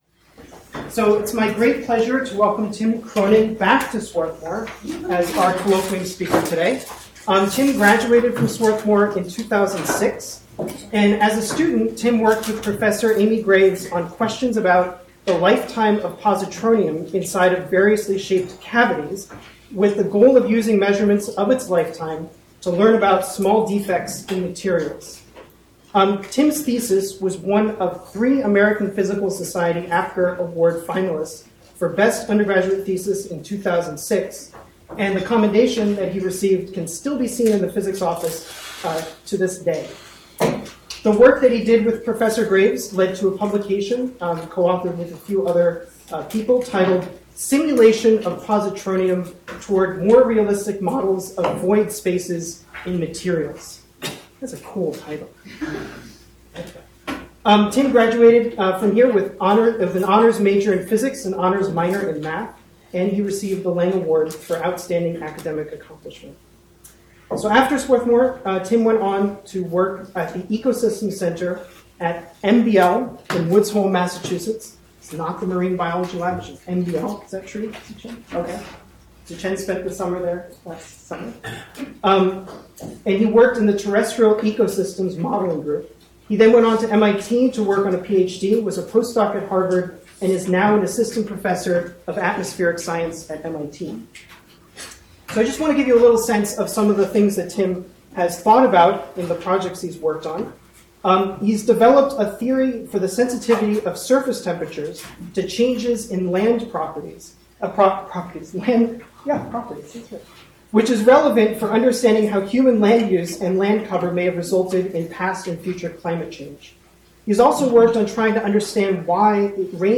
presents at the Swarthmore Physics and Astronomy Colloquium.